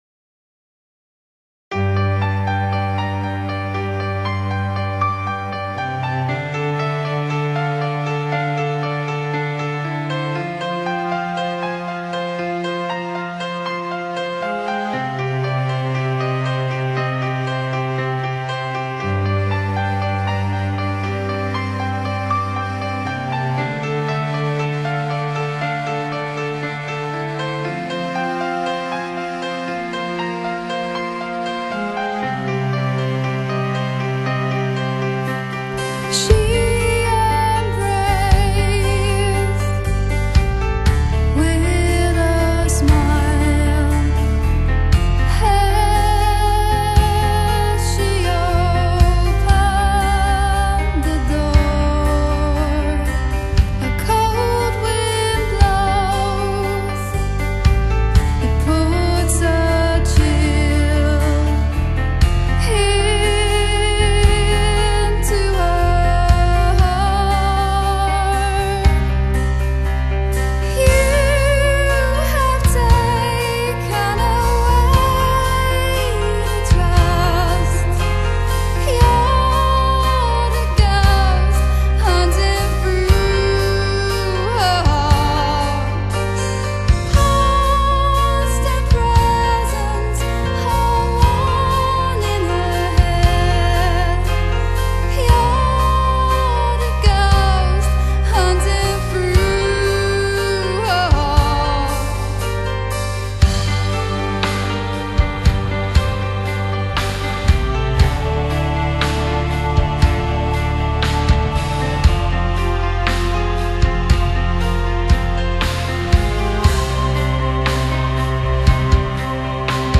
Symphonic-Gothic Rock
女主唱美声震撼欧陆
整张专集主要是采用中速，并且有鲜明的主旋律